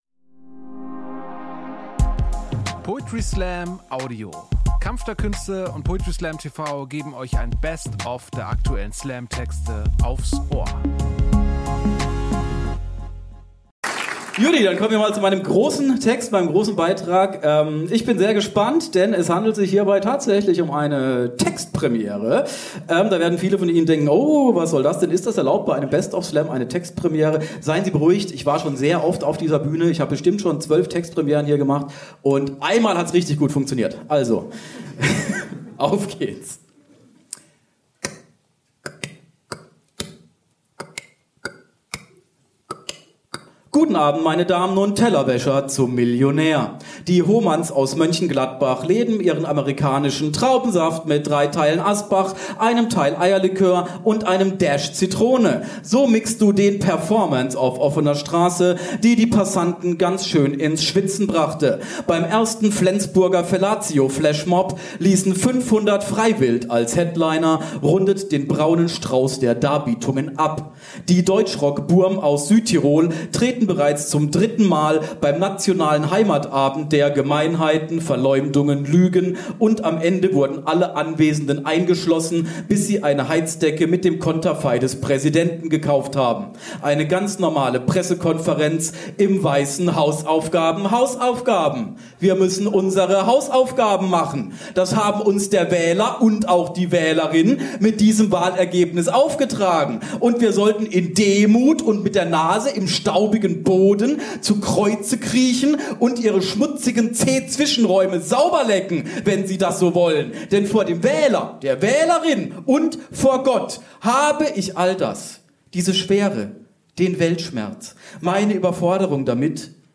Stage: Ernst Deutsch Theater, Hamburg